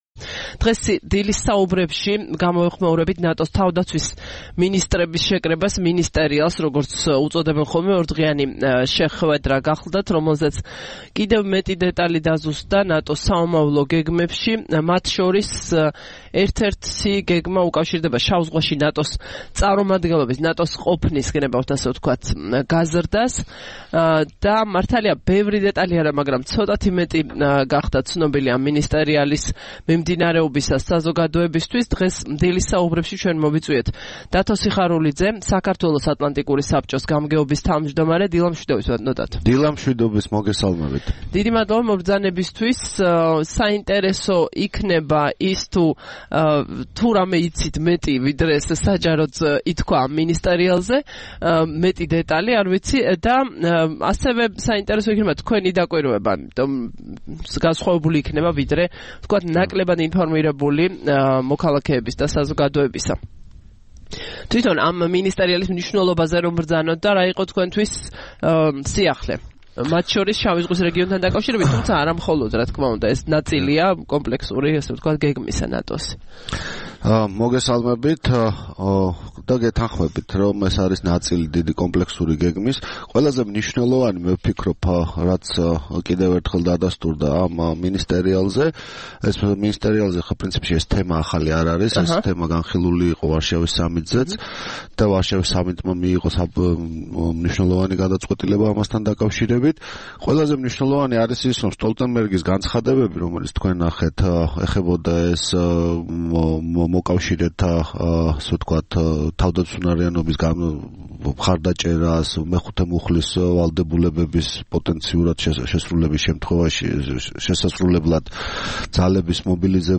სტუმრად ჩვენს ეთერში: დათო სიხარულიძე